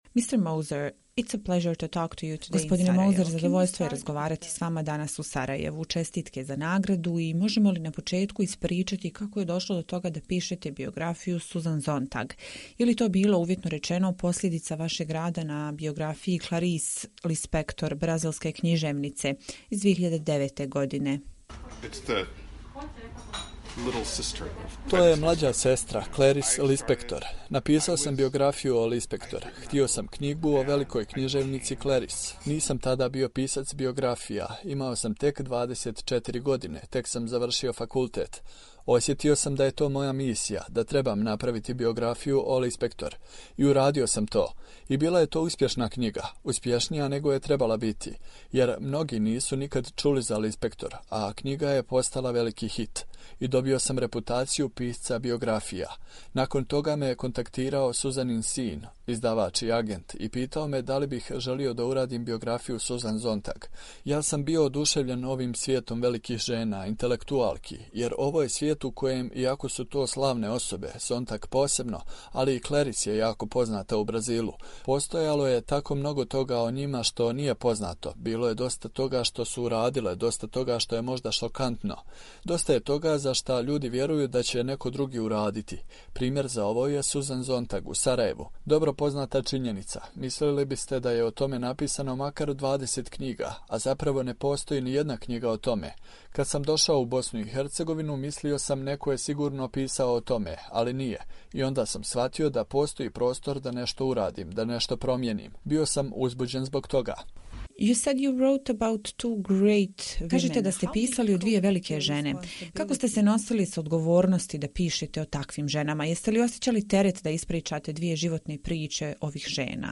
Intervju: Benjamin Moser, dobitnik Pulitzerove nagrade za djelo 'Sontag: Život i djelo'